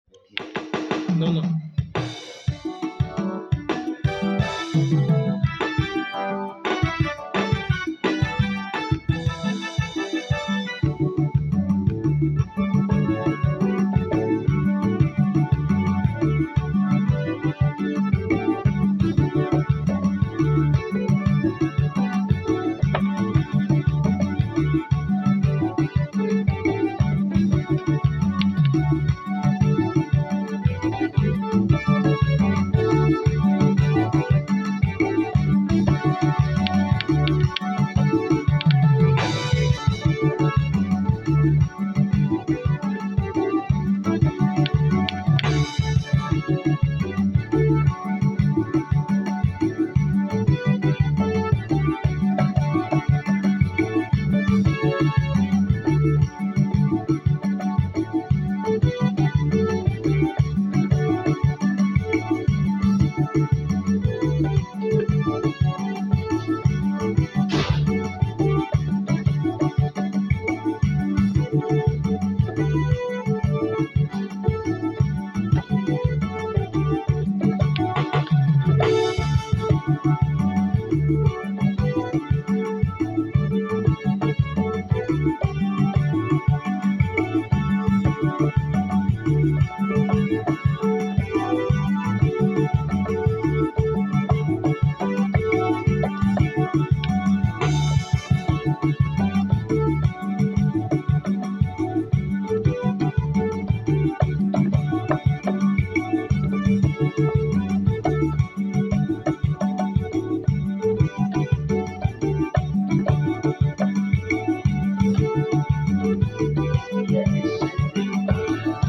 Chants d’Action de Grâce